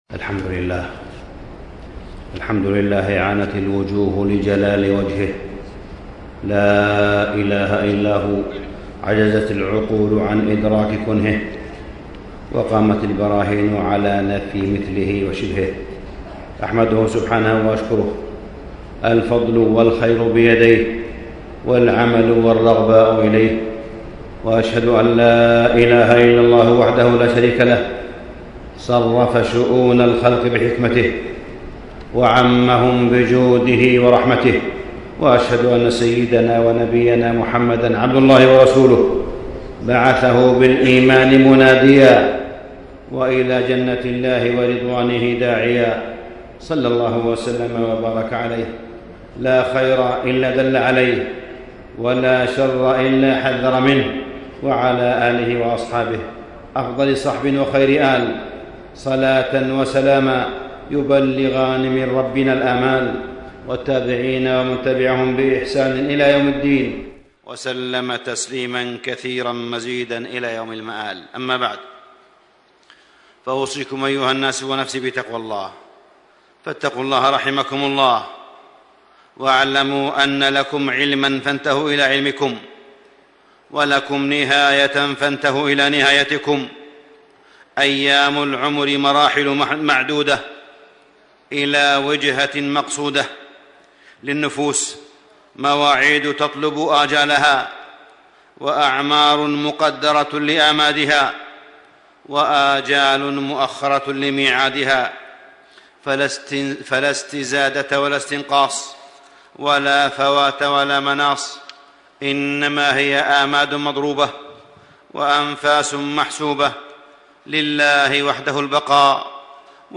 تاريخ النشر ٢١ ربيع الثاني ١٤٣٥ هـ المكان: المسجد الحرام الشيخ: فضيلة الشيخ د. أسامة بن عبدالله خياط فضيلة الشيخ د. أسامة بن عبدالله خياط الأسباب الجالبة للرزق